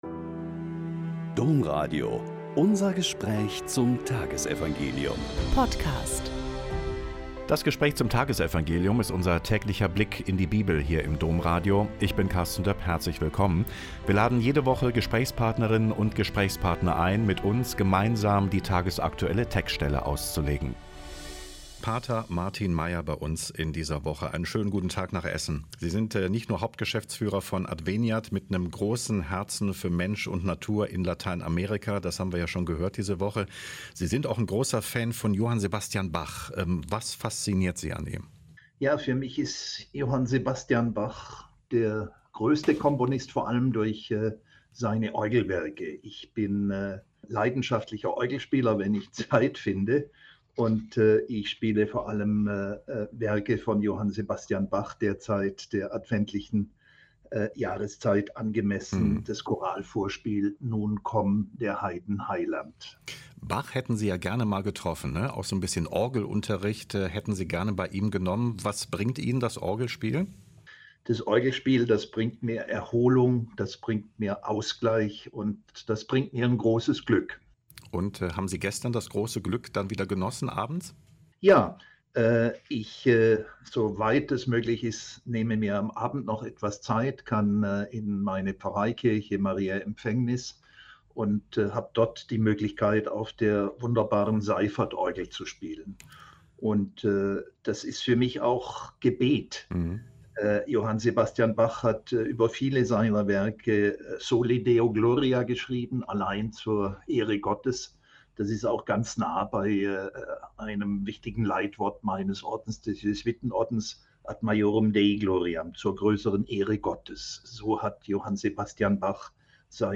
Mt 11,7b.11-15 - Gespräch